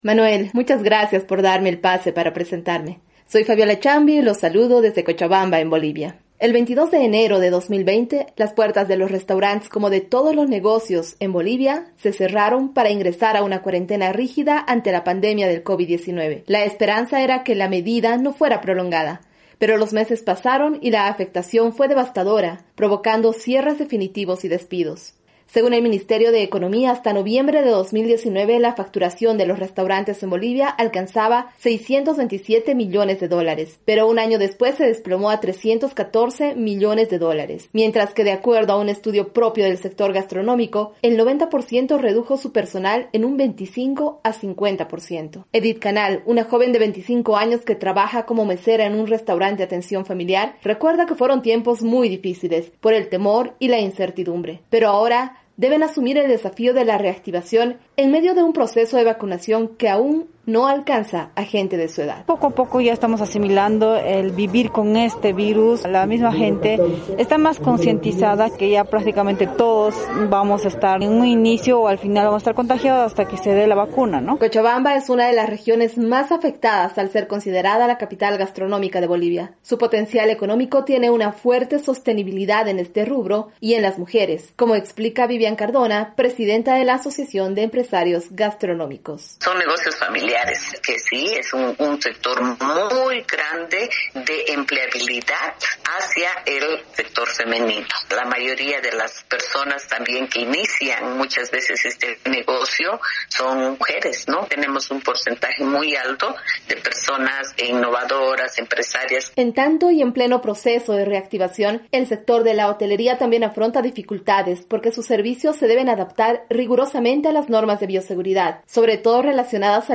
Las mujeres que trabajan en el sector de servicios como restaurantes y hoteles enfrentaron un año de pandemia entre la incertidumbre y la inseguridad sanitaria. Reportaje especial